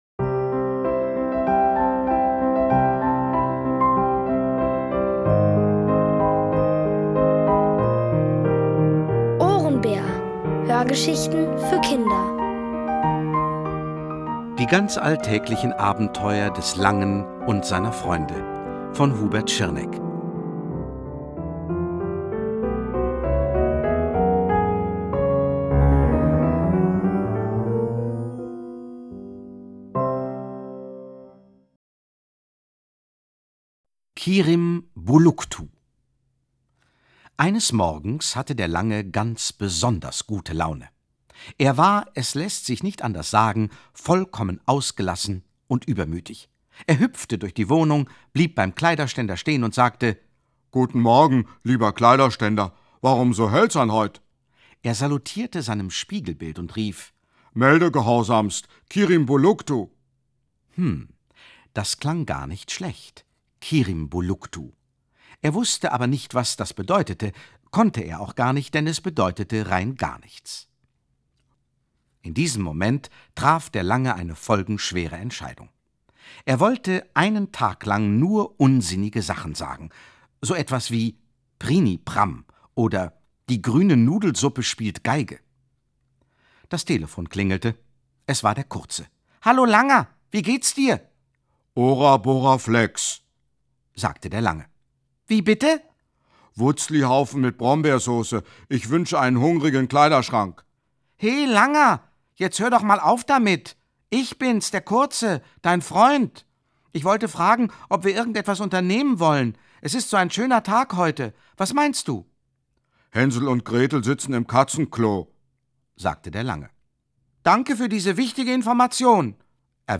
Von Autoren extra für die Reihe geschrieben und von bekannten Schauspielern gelesen.
liest: Gerd Wameling.